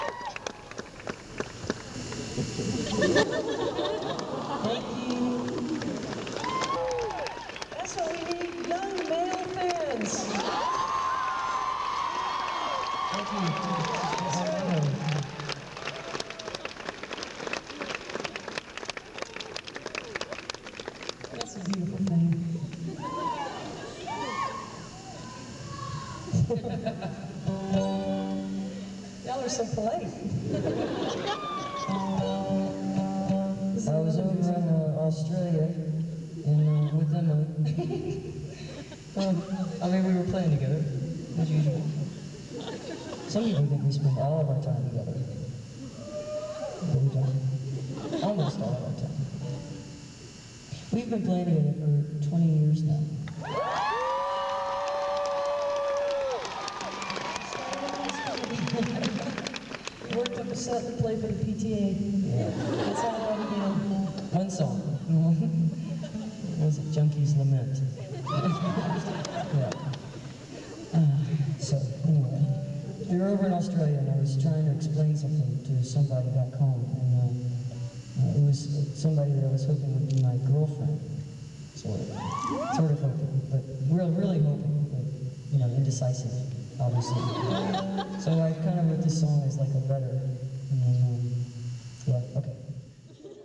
10. talking with the crowd (1:33)